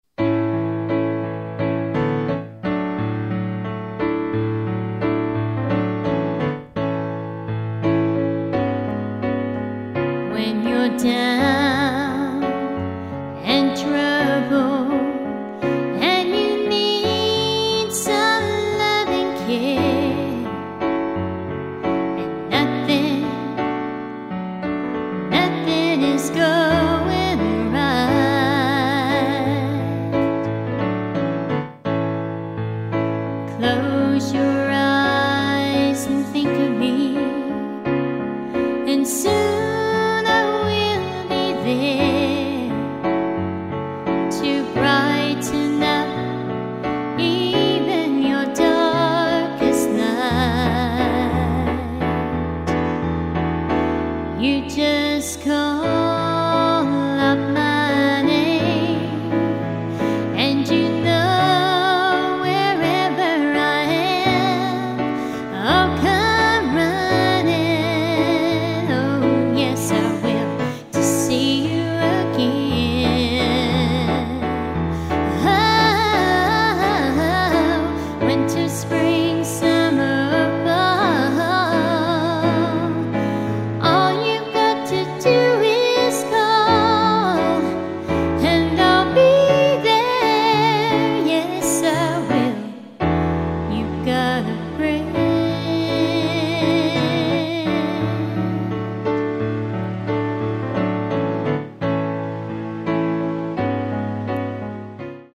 Covers